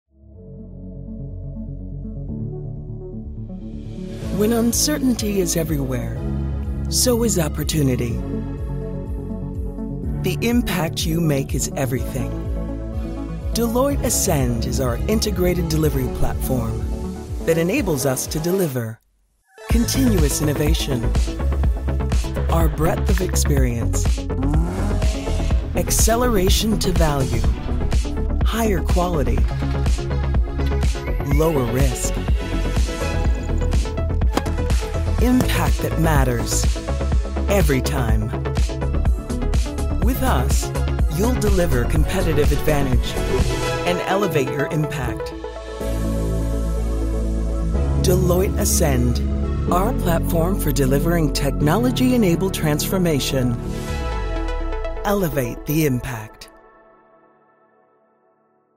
30s-50s. Strong African American voice. Warm, in control, great for corporate work. Home Studio.
Corporate